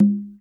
SingleHit_QAS10768.WAV